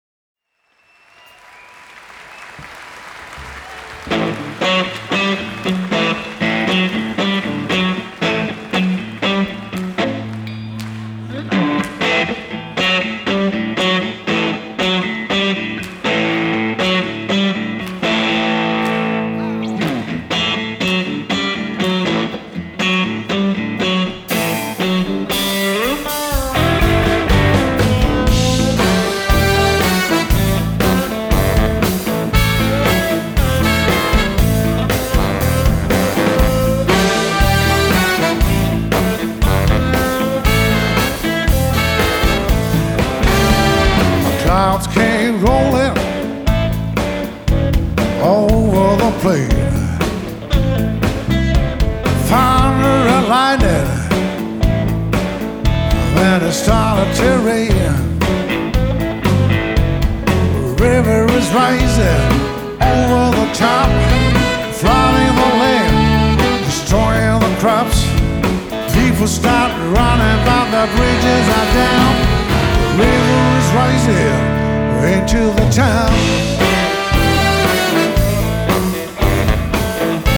發燒男聲、發燒天碟